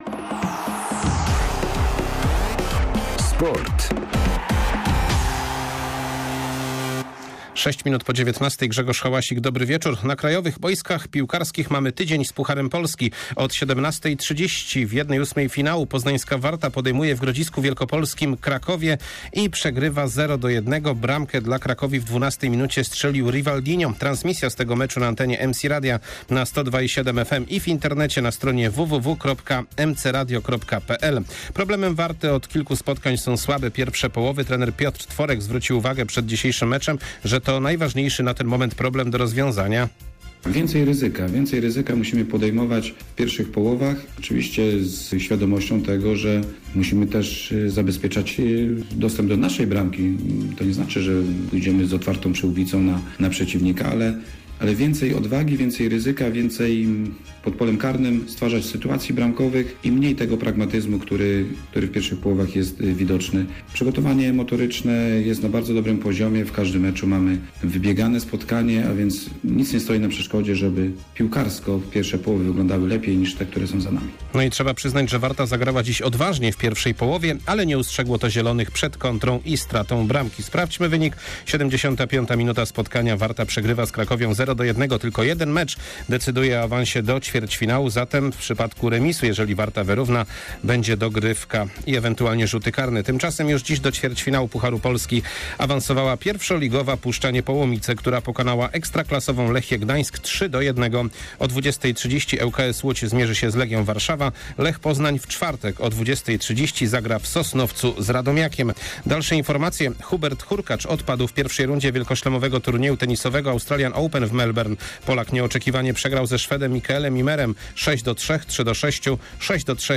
09.02.2021 SERWIS SPORTOWY GODZ. 19:05